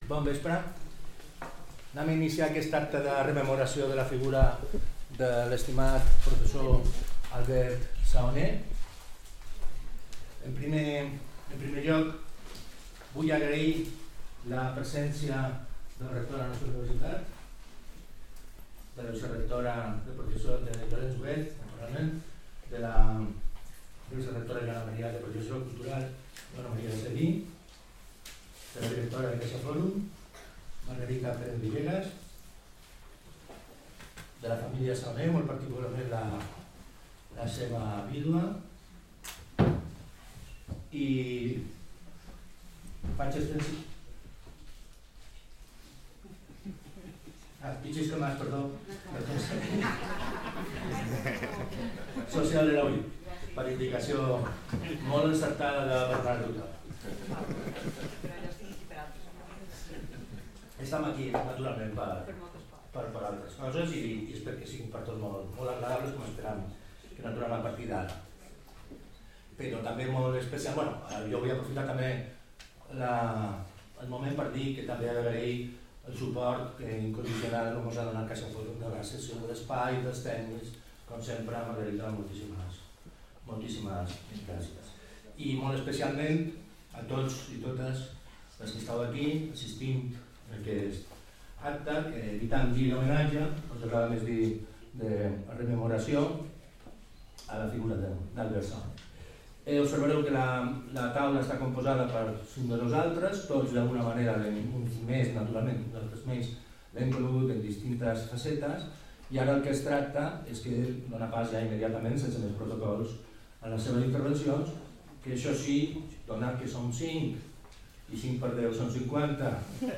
Acte d'homenatge amb taula rodona, dia 24 de maig a les 19h.
CaixaFòrum Palma, Plaça Weyler, nº3, Palma